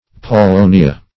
Paulownia \Pau*low"ni*a\, prop. n. [NL.